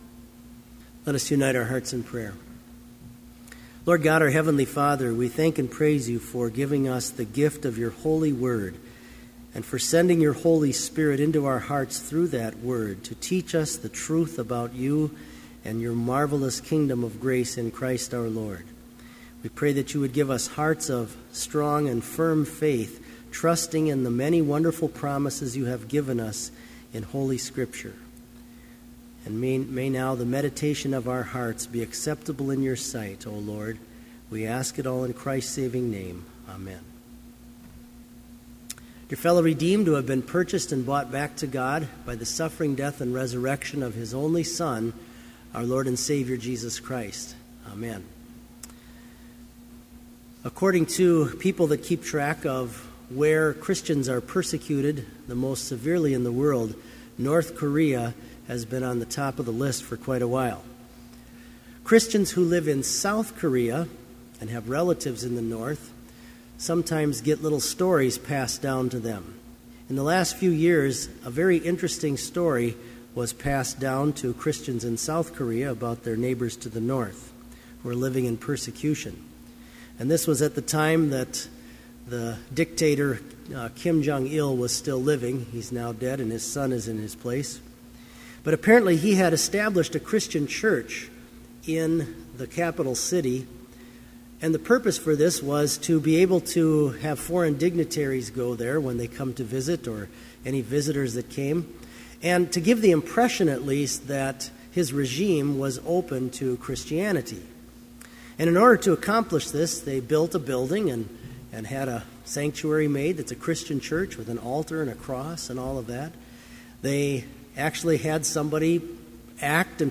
Complete Service
• Homily
This Vespers Service was held in Trinity Chapel at Bethany Lutheran College on Wednesday, February 6, 2013, at 5:30 p.m. Page and hymn numbers are from the Evangelical Lutheran Hymnary.